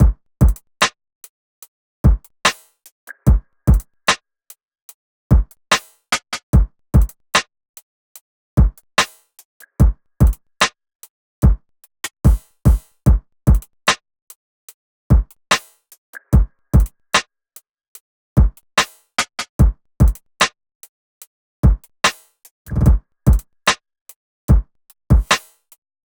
AV_Diamonds_Drums_147bpm